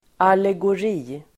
Uttal: [alegor'i:]